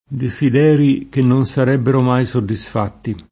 deSid$ri ke nnon Sar$bbero m#i SoddiSf#tti] (Manzoni) — nell’uso ant., anche desidero [deSid$ro], disidero [diSid$ro], disiderio [diSid$rLo]: con tanto desidero questo fatto aspettavano [kon t#nto deSid$ro kU%Sto f#tto aSpett#vano] (Boccaccio); non iscusando il suo disidero perverso [